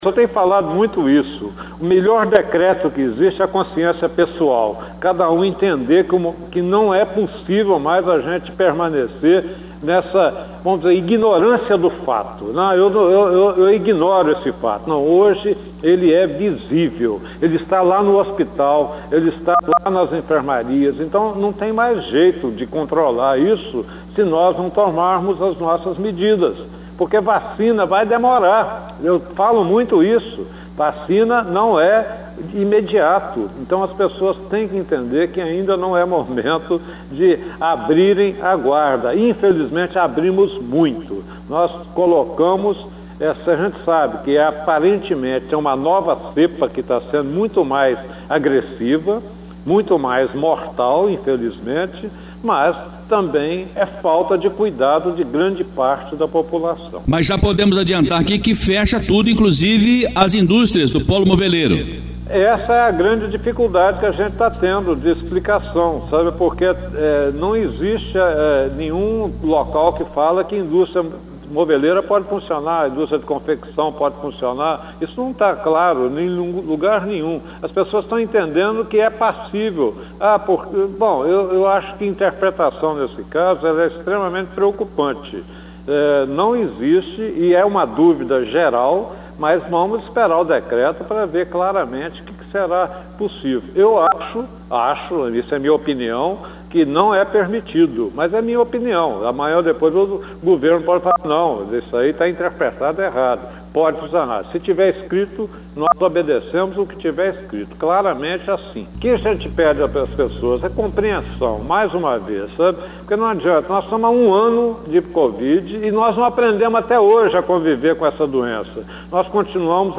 Em entrevista ao jornal em dia com a Notícia da Rádio Educadora AM/FM nesta terça-feira(16/03), o prefeito Edson Teixeira Filho, disse, que pode acontecer uma paralisação total das atividades no município, isso inclui as indústrias moveleiras da cidade.
Parte da entrevista